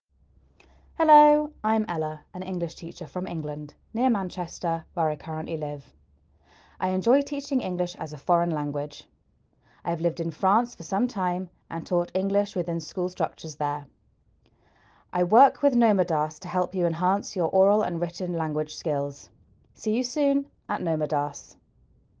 The english teachers are talking to you!